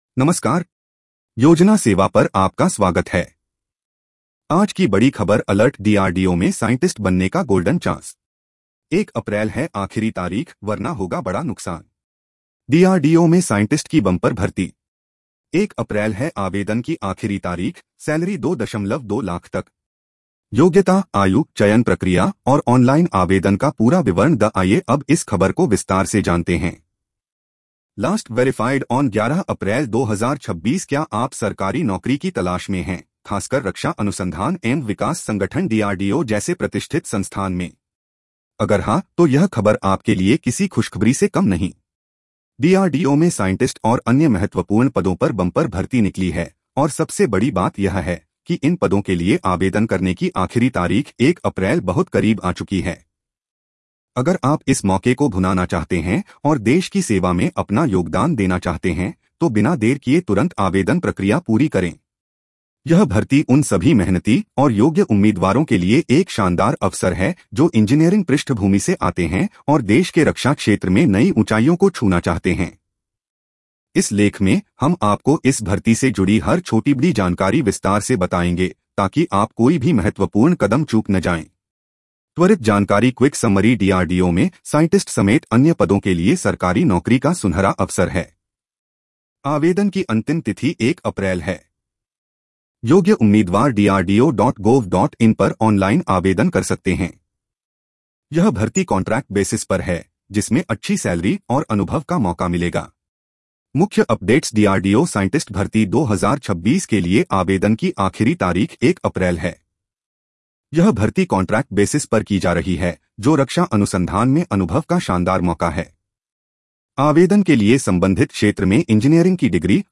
News Audio Summary
🎧 इस खबर को सुनें (AI Audio):